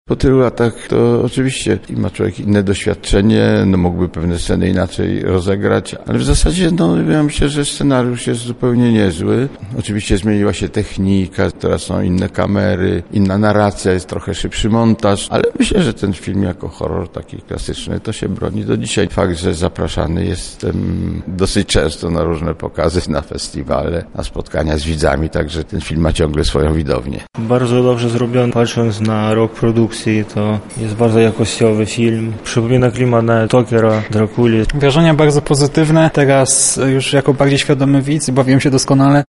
Po seansie widzowie spotkali się z reżyserem filmu Markiem Piestrakiem.